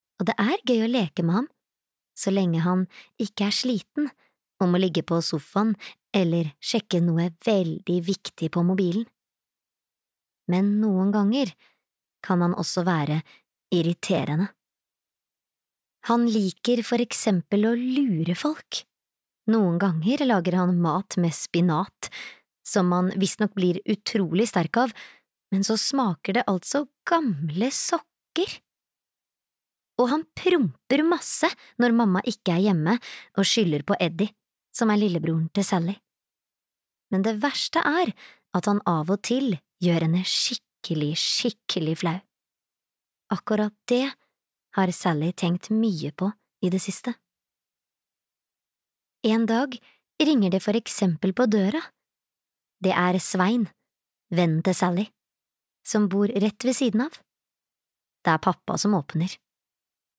Sallys pappa gjør henne sykt flau (lydbok) av Thomas Brunstrøm